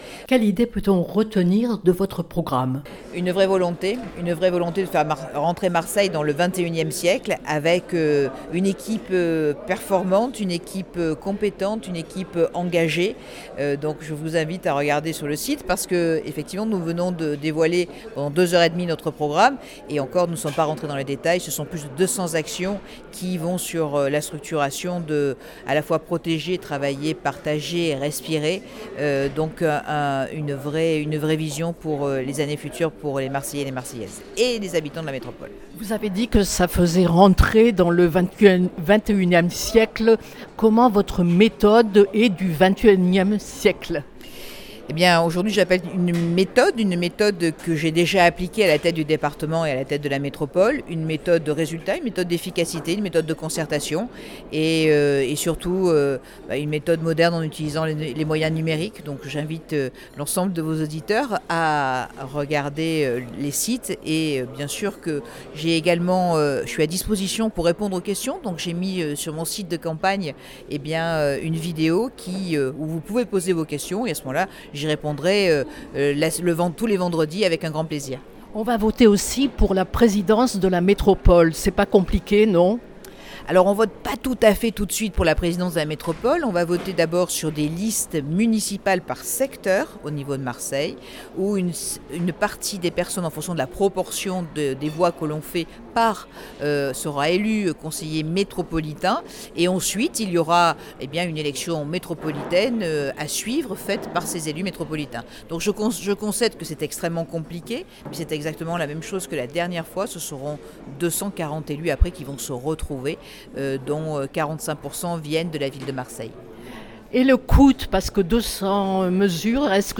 Un programme comprenant plus de 200 actions dans lequel elle affirme notamment vouloir tripler les effectifs de la police municipale, développer une meilleure offre de soins, les transports et les espaces verts. Entretien.
son_copie_petit-408.jpg Entretien avec Martine Vassal, candidate LR à la mairie de Marseille